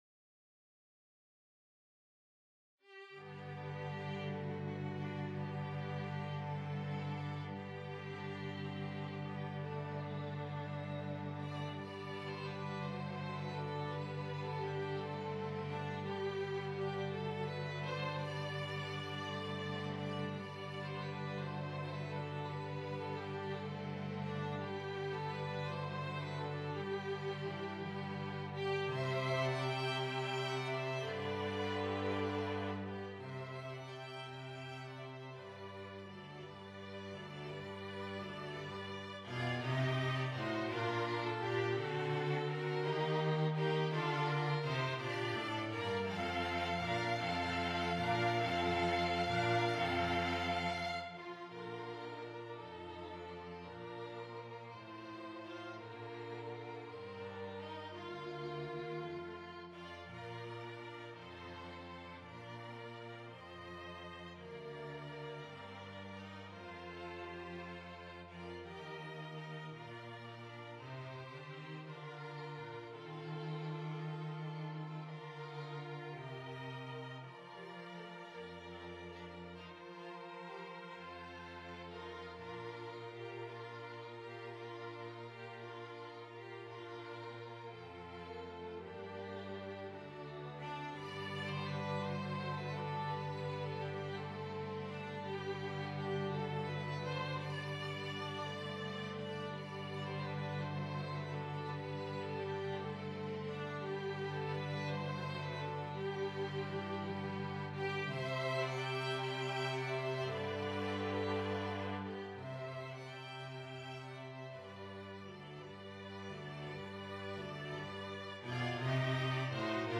string quartet
arranged for string quartet